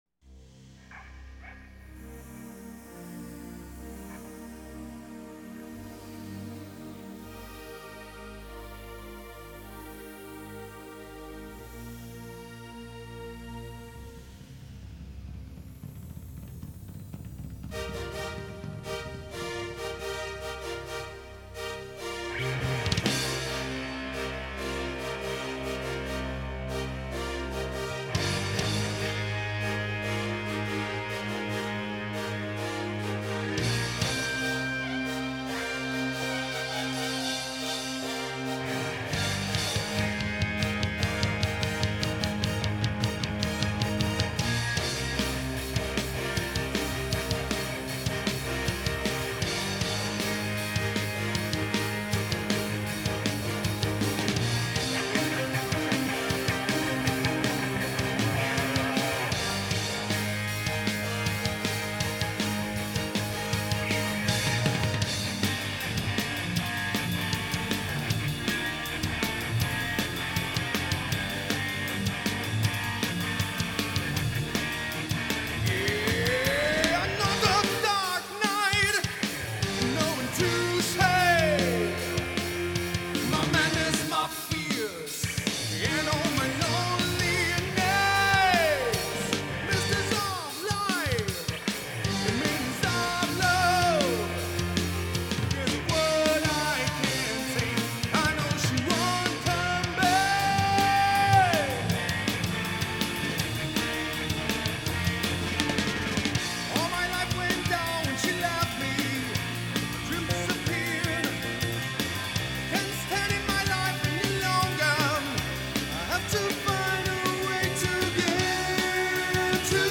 We recorded these two songs in a rented 24 track studio.
Great bass line (as usual) and a great bass solo!